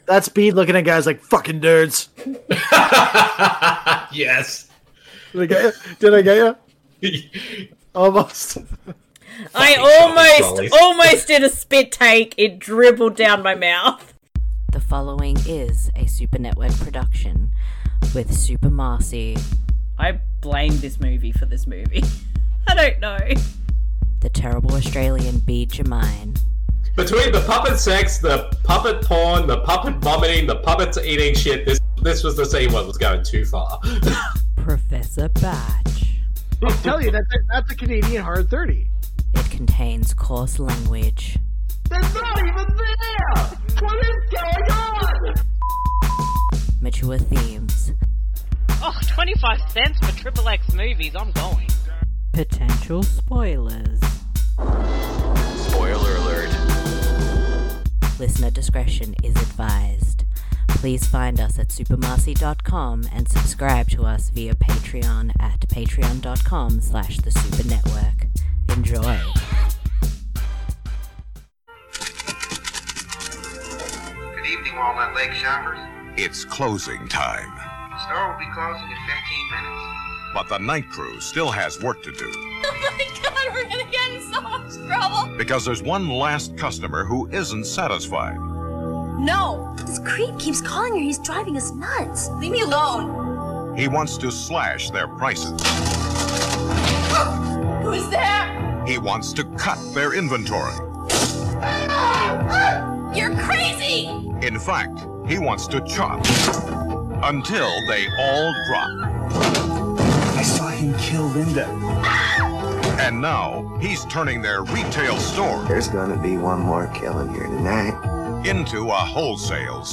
This podcast series is focused on discovering and doing commentaries for films found on the free streaming service Tubi, at TubiTV